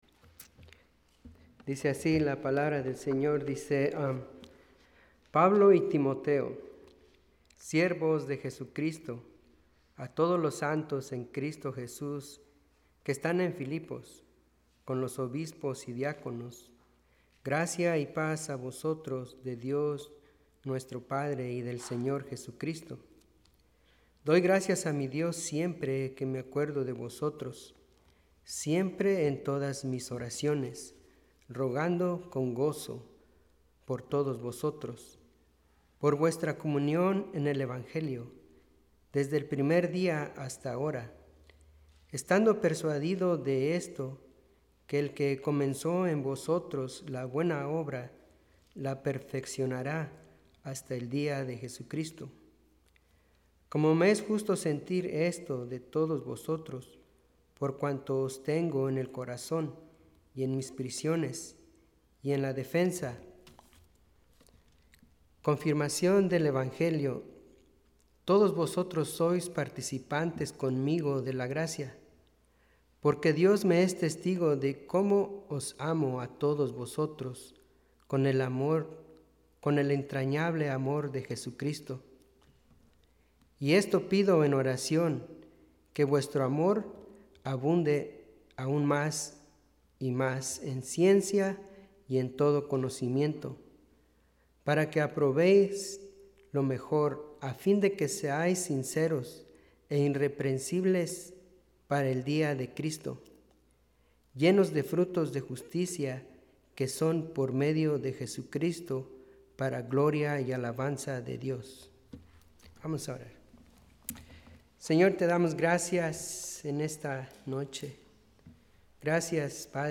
Un mensaje de la serie "Invitado Especial."